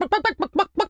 chicken_cluck_bwak_seq_10.wav